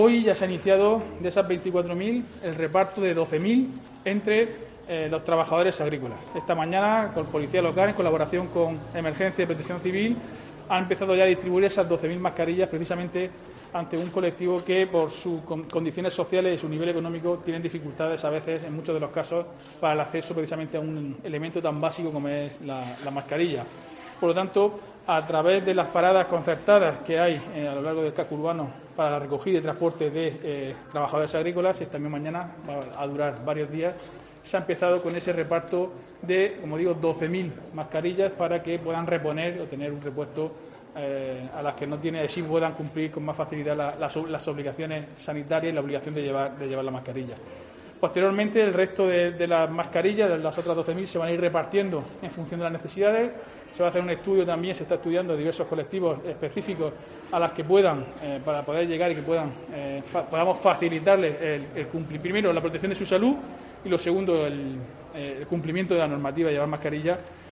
Diego José Mateos, alcalde de Lorca sobre mascarillas